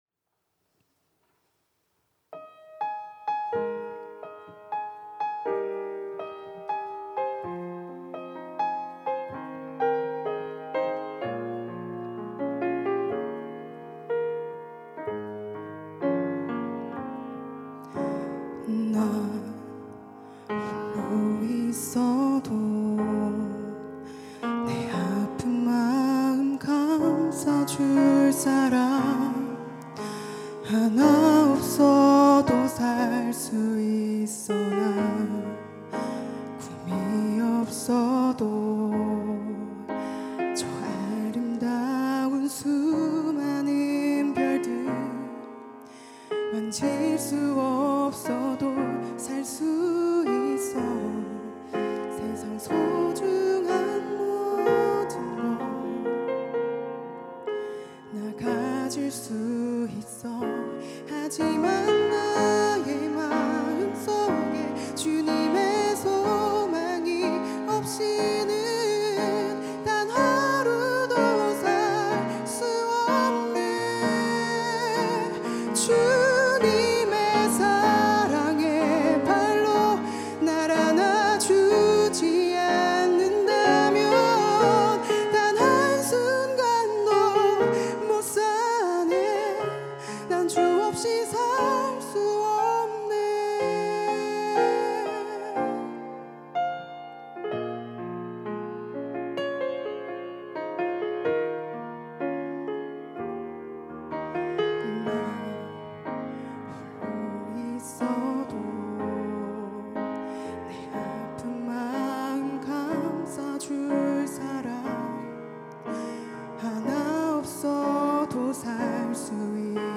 특송과 특주 - 주 없이 살 수 없네